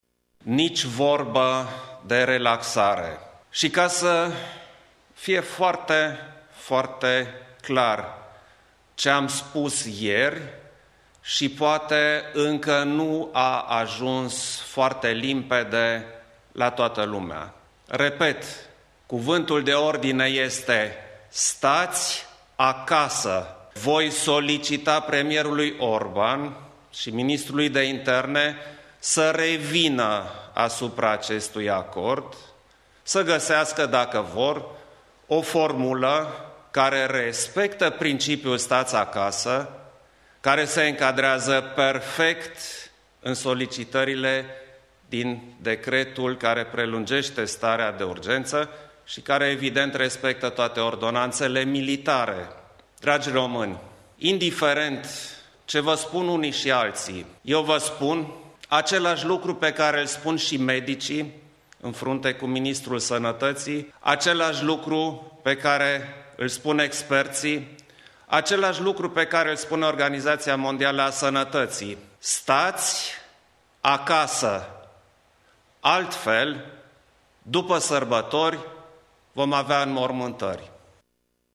Preşedintele Klaus Iohannis a declarat că va solicita în şedinţa cu premierul şi mai mulţi miniştri, programată să înceapă la 12,30, să se revină asupra acordului încheiat între Ministerul Afacerilor Interne şi Biserica Ortodoxă Română privind sărbătoarea Paştelui.
stiri-15-apr-decl-iohannis.mp3